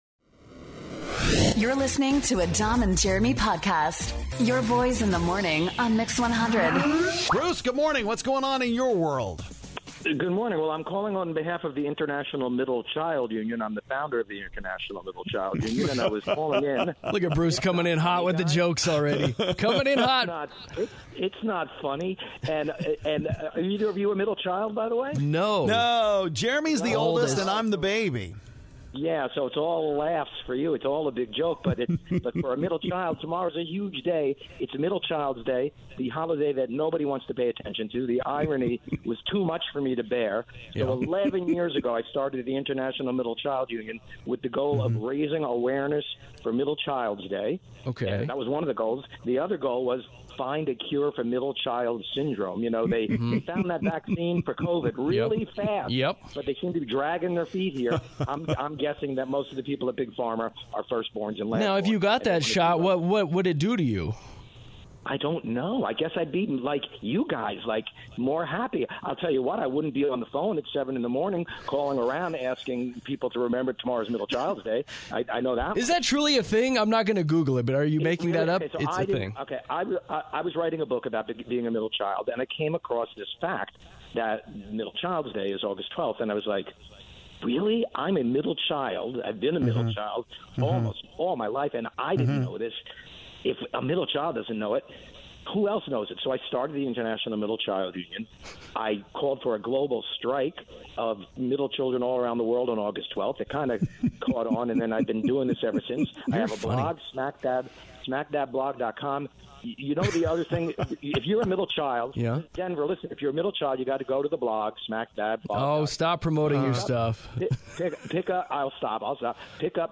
We have you guys call us and talk about whatever is on your minds!